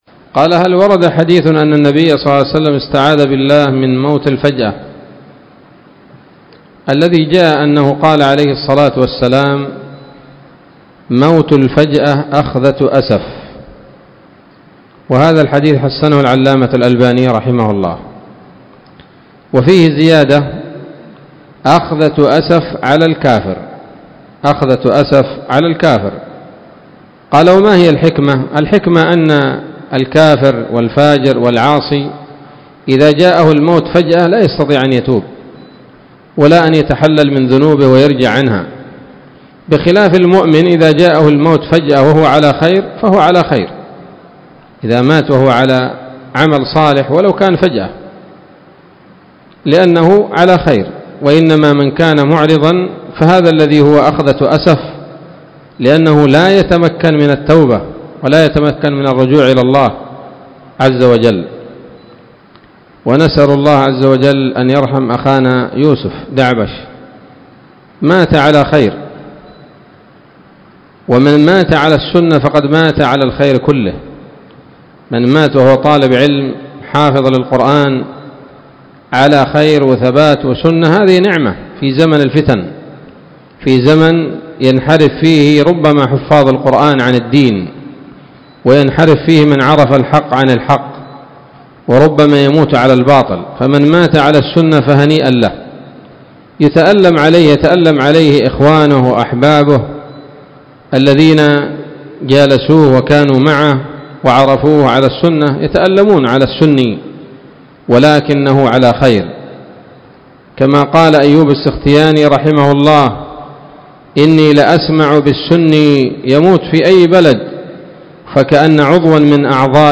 كلمة بعنوان